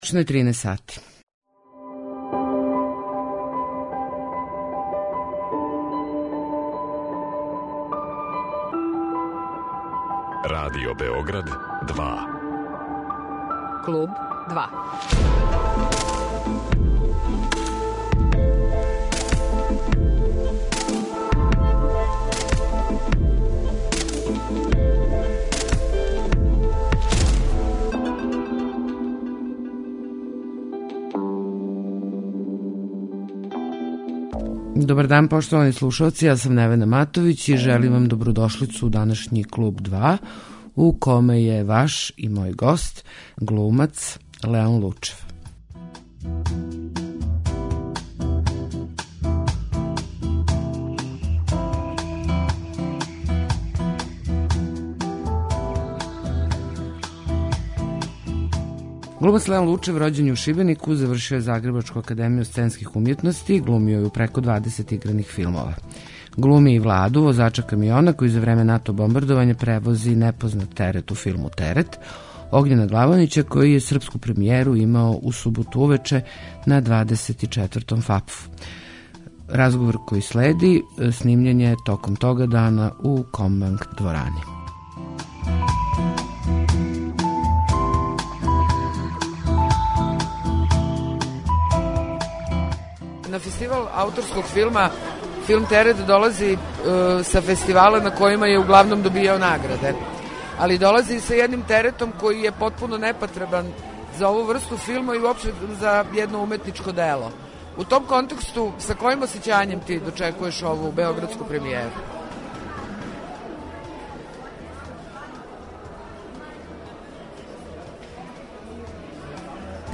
Леон Лучев, гост емисије
Разговор који ћете чути снимљен је на дан српске премијере Терета, у Kомбанк дворани, једном од биоскопа 24. Фестивала ауторског филма.